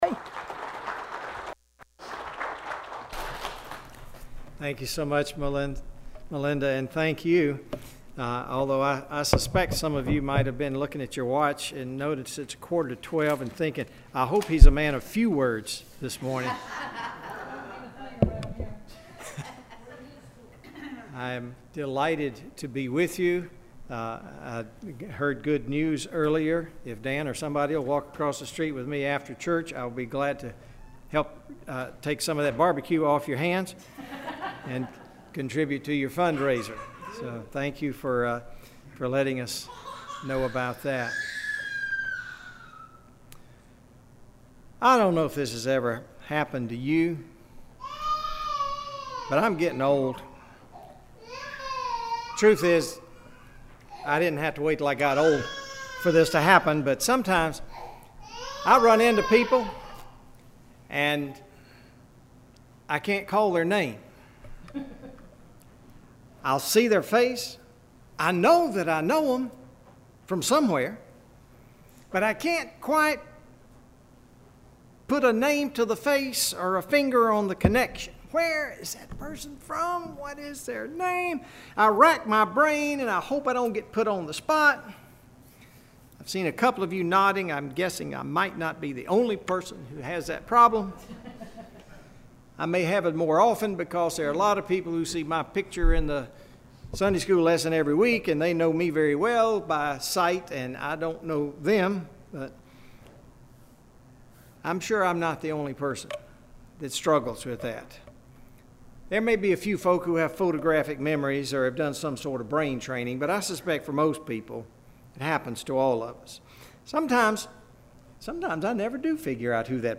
This weeks sermon: